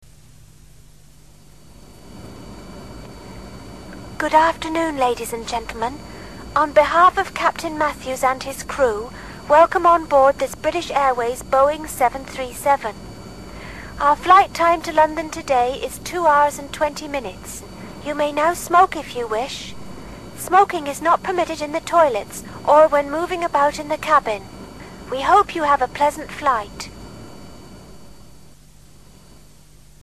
Announcements (II)
ACTIVITY 172: Now, you will hear an announcement during a flight.